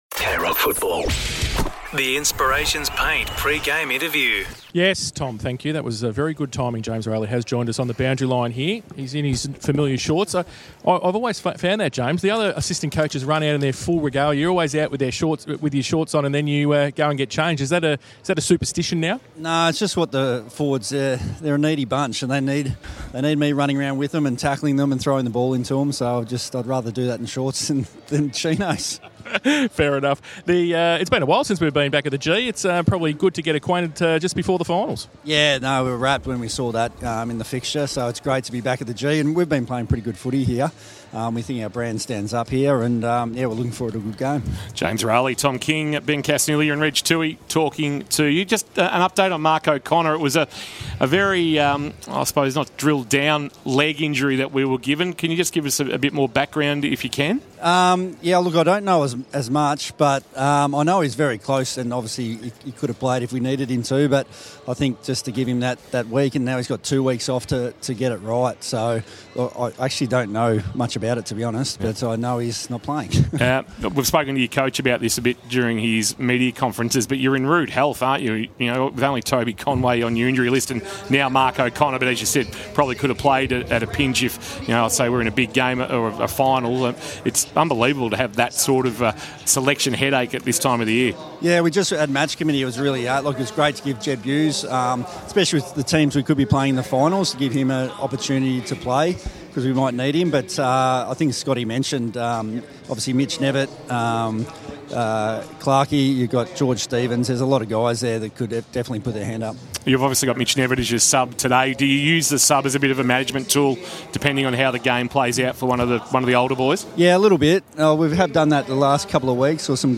2025 - AFL - Round 24 - Richmond vs. Geelong - Pre-match interview